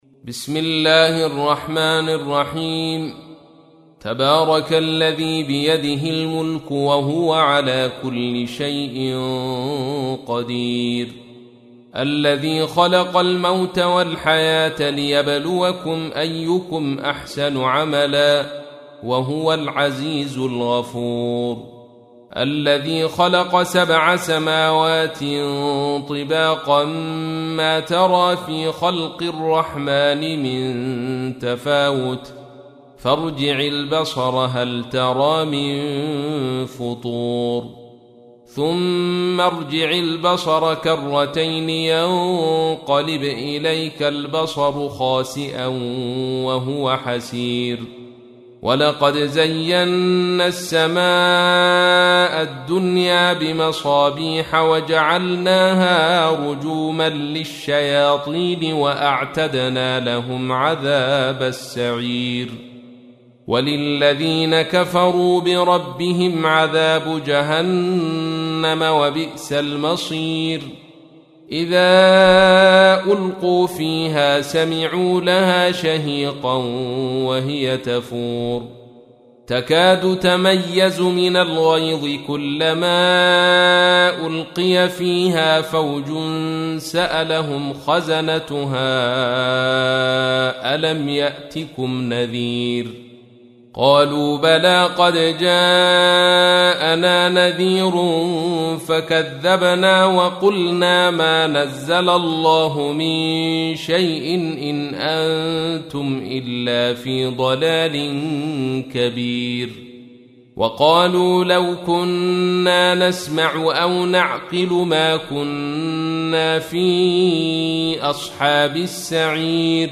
تحميل : 67. سورة الملك / القارئ عبد الرشيد صوفي / القرآن الكريم / موقع يا حسين